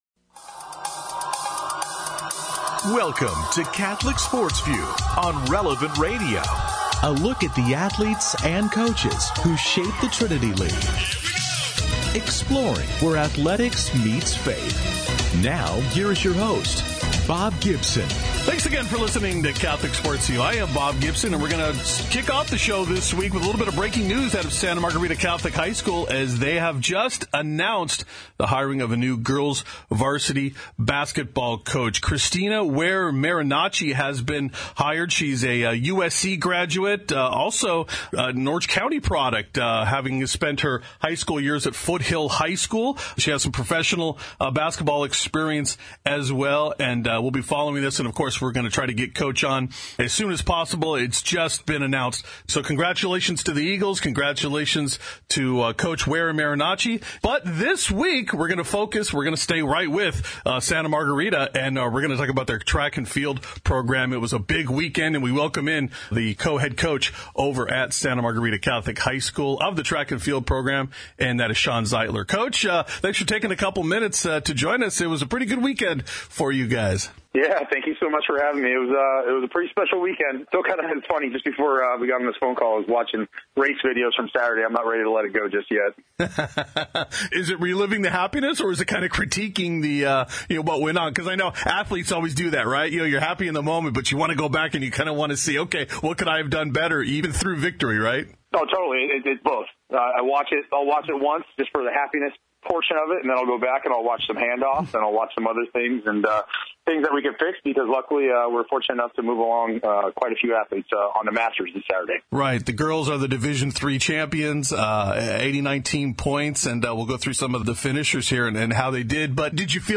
interviews coaches and players throughout the various Catholic high schools in Orange County.